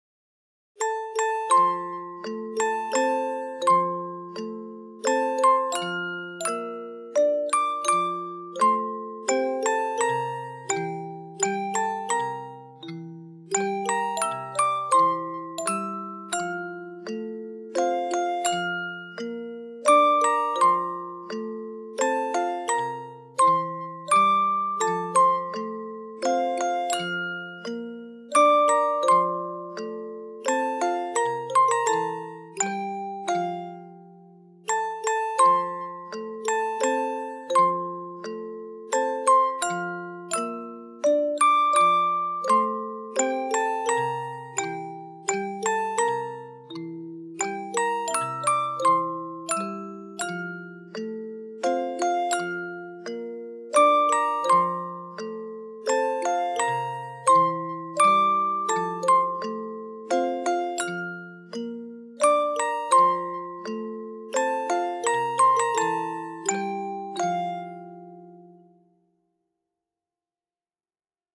Christmas Lullaby